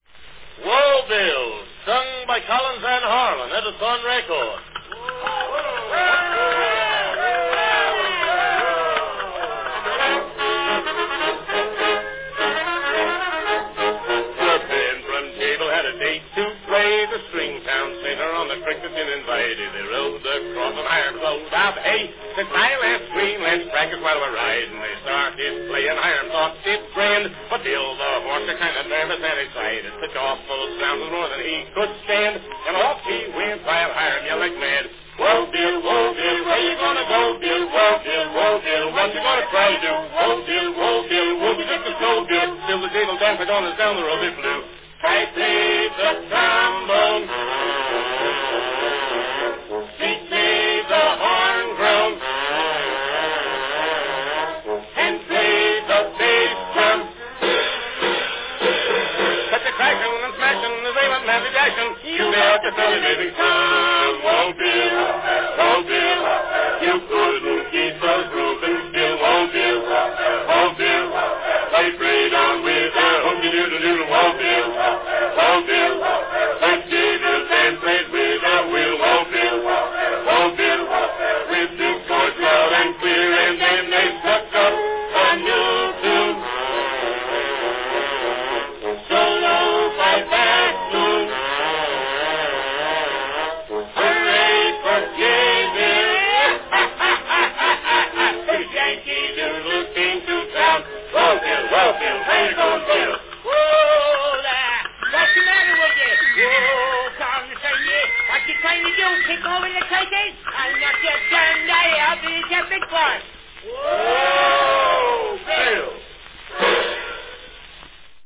A wild west duet from 1904
Category Duet